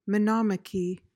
PRONUNCIATION:
(muh-NOM-uh-kee)